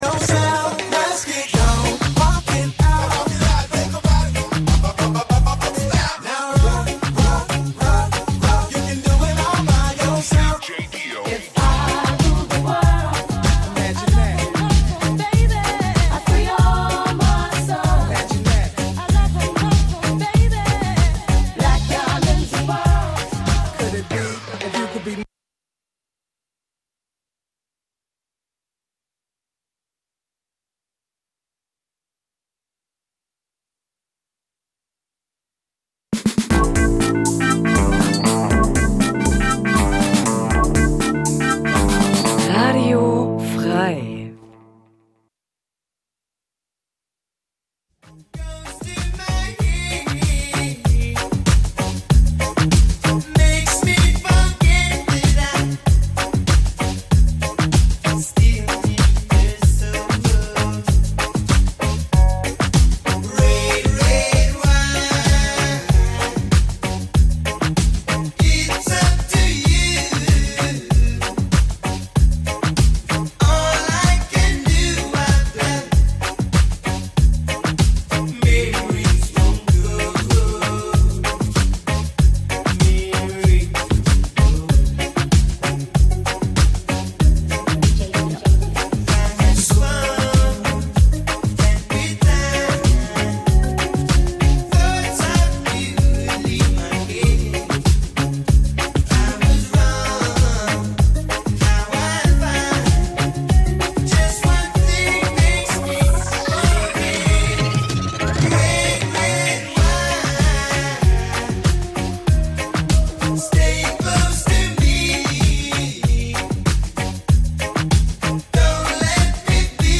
Reggae, Ska, Dub Dein Browser kann kein HTML5-Audio.
Reggae, Ska, Dub von alt bis neu.